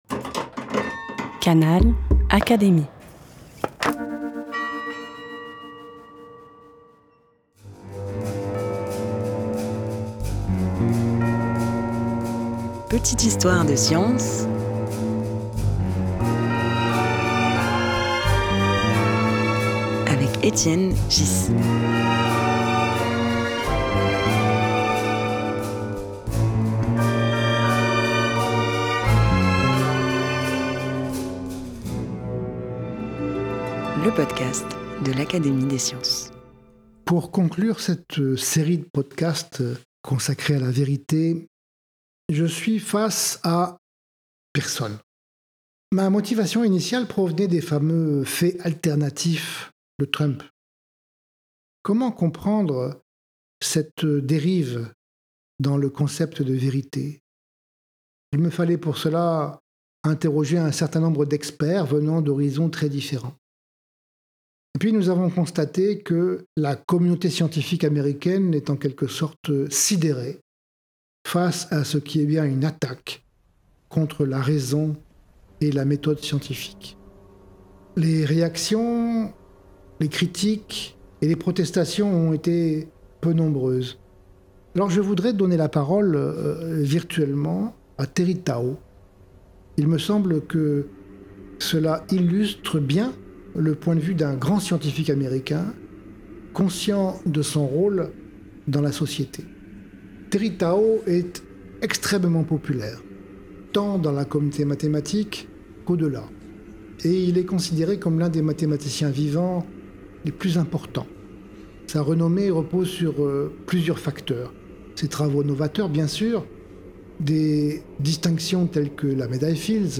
Pour clore cette série consacrée à la vérité, Étienne Ghys lit des extraits du blog de Terence Tao, médaille Fields et figure majeure des mathématiques contemporaines. Depuis l’élection de Donald Trump en 2016, Terence Tao partage ses réflexions sur un phénomène préoccupant : l’instrumentalisation de la vérité à des fins politiques.
Un podcast animé par Étienne Ghys, proposé par l'Académie des sciences.